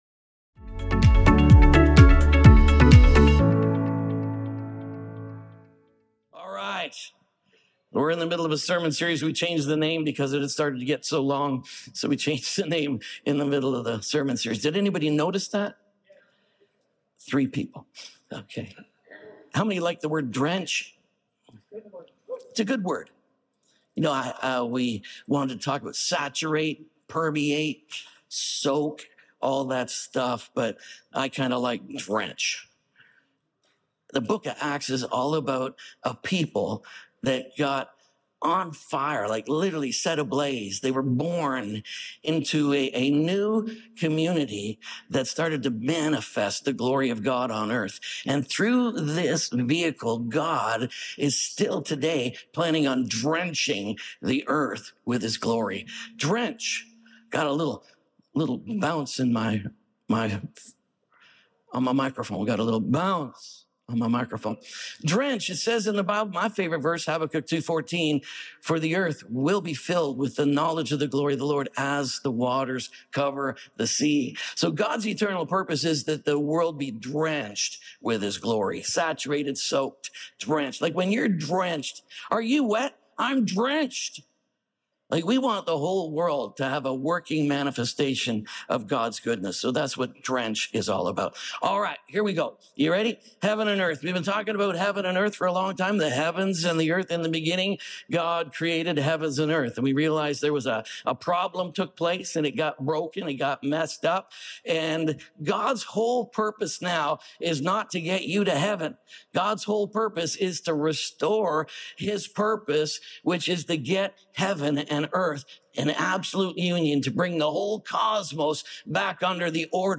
COMMUNITY HEAVEN AND EARTH | SERMON ONLY.mp3